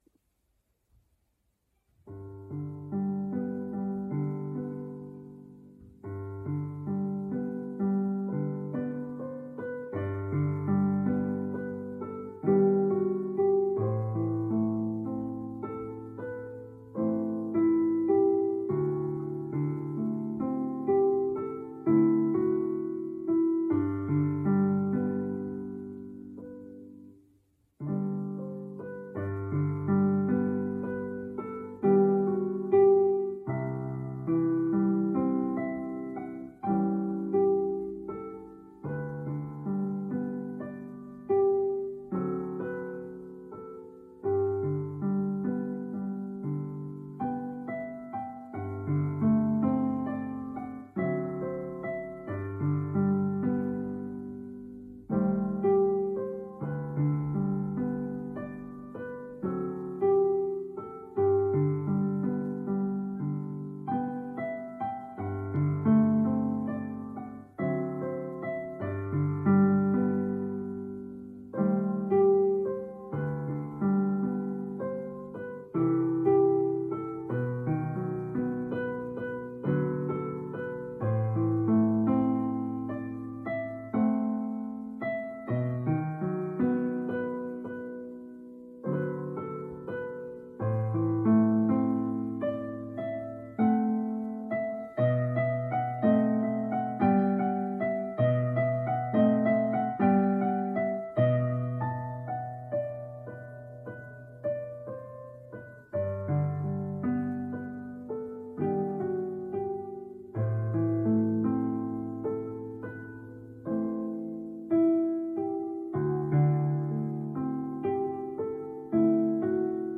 Végre félreütés nélkül (este 19 órai rögzítés)/ Finalmente senza una nota fuori posto (registrazione alle 19):